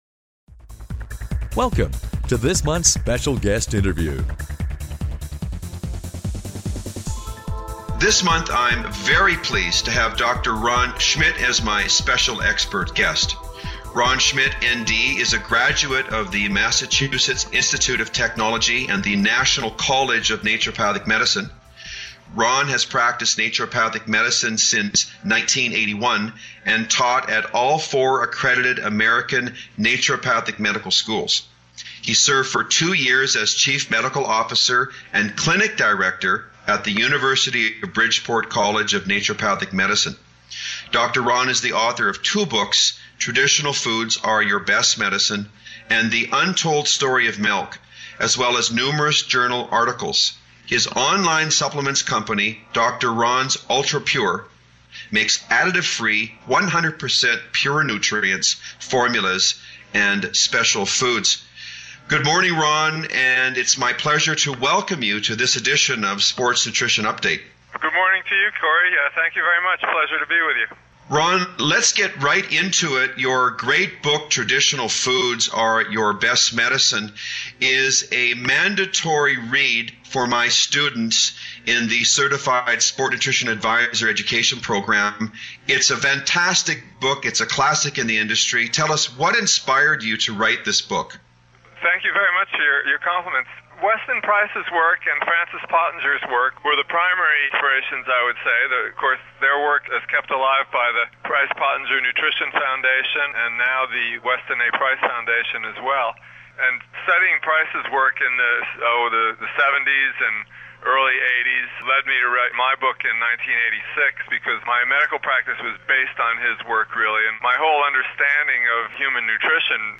Special Guest Interview Volume 7 Number 4 V7N4c